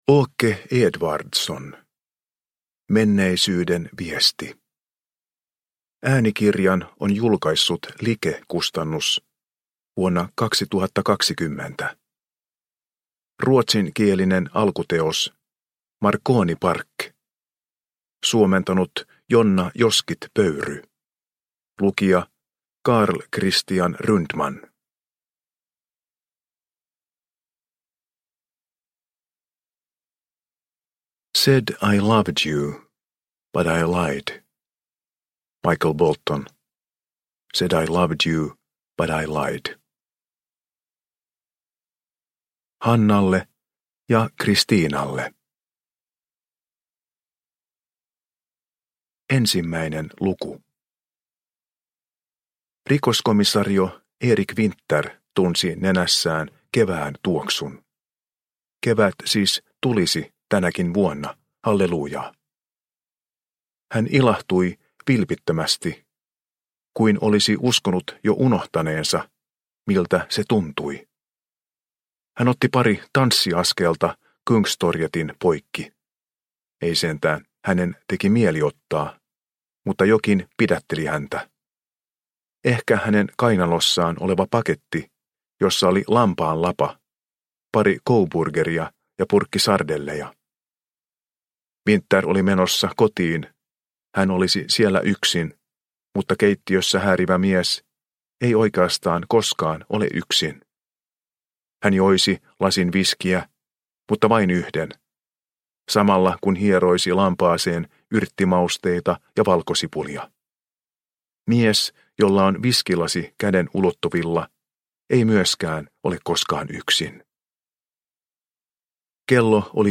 Menneisyyden viesti – Ljudbok – Laddas ner